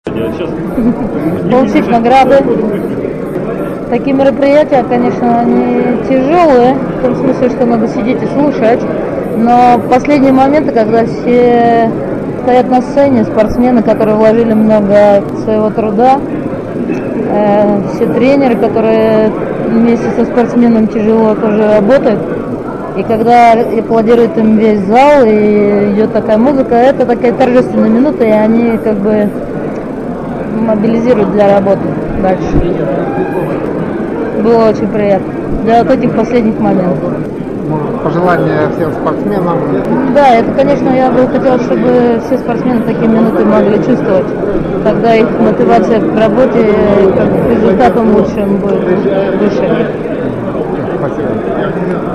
Интервью( аудиоверсии)
23 сентября 2009 года. Совещание в Доме искусств "Кузьминки" физкультурно-спортивного актива Московской области